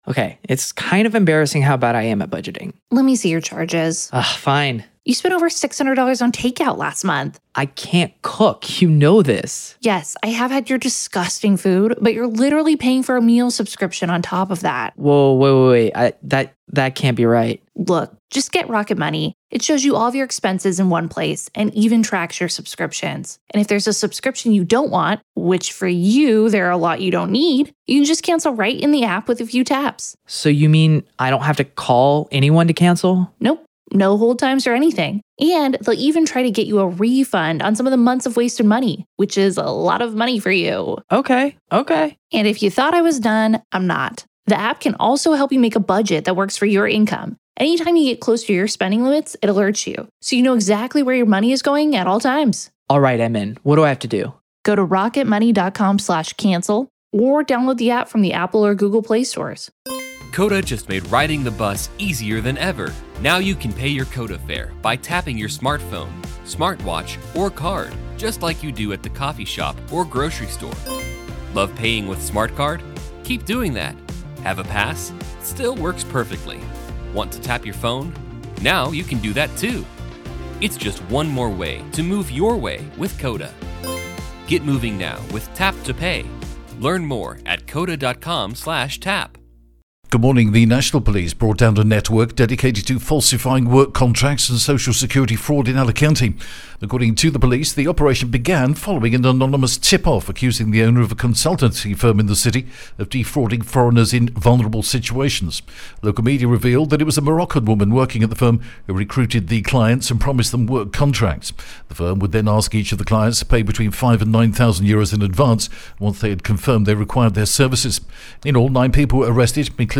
The latest Spanish news headlines in English: 19th December 2022